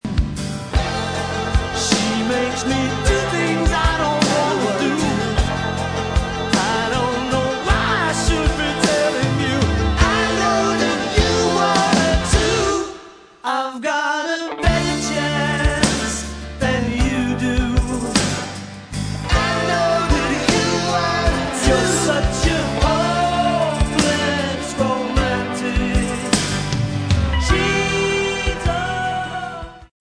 Lead Vocal, Bass, Guitars, Harmonies
Second Vocals, Intro Keyboard
Drums, Percussion
Keyboards
End Orchestration